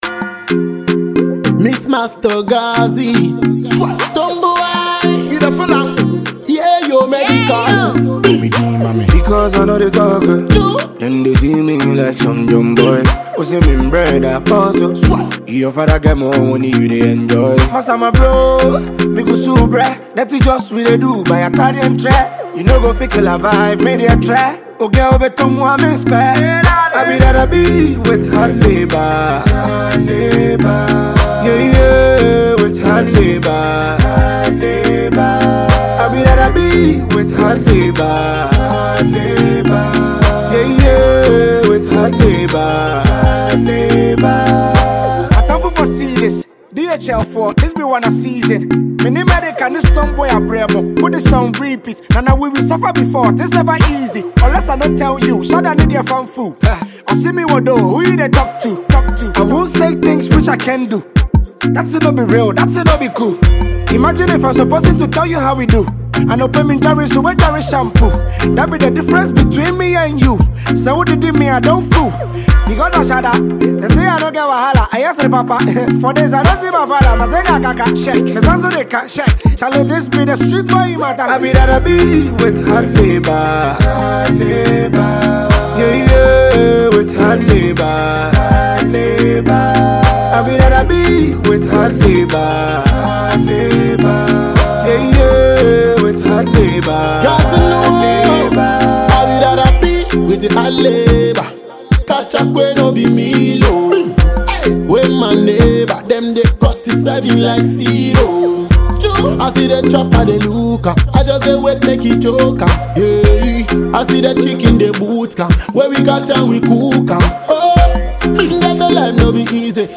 afrobeat banger